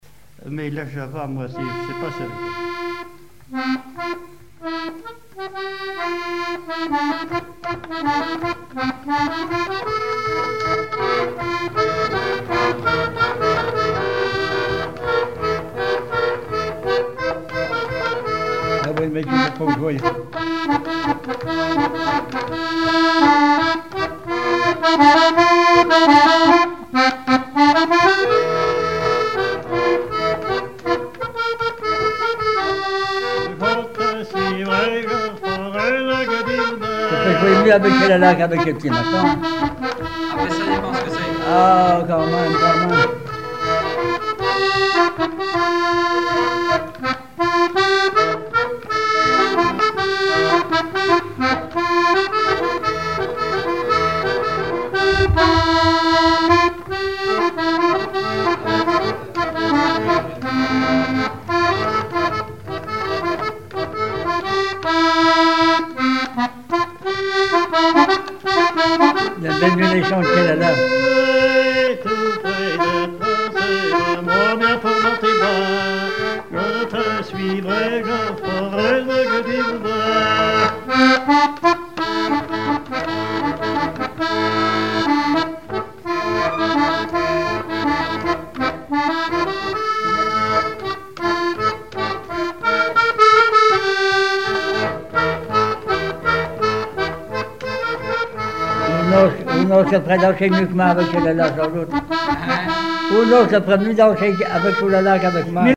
Mémoires et Patrimoines vivants - RaddO est une base de données d'archives iconographiques et sonores.
danse : java
chansons populaires et instrumentaux
Pièce musicale inédite